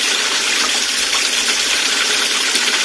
Звук текущей воды.